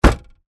Звук захлопывающейся двери автомобиля